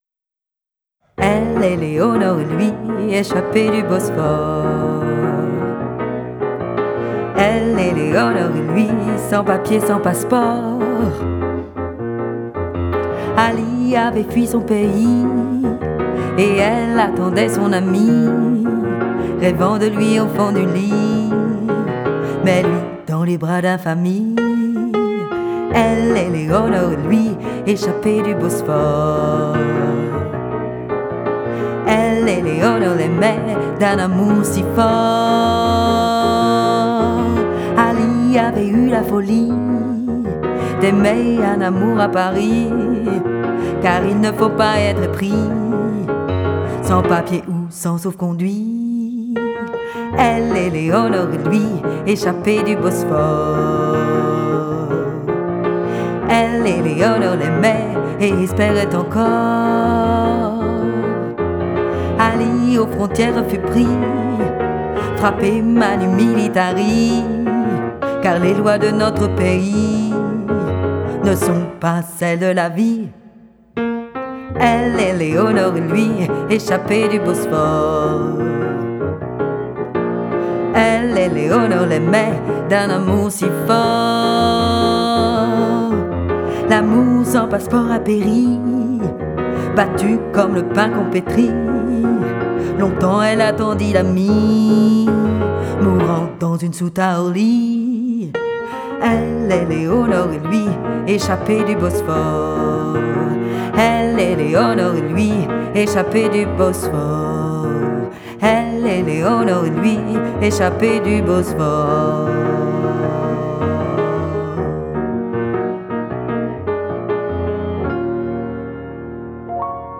Piano
Les deux premières (maquette)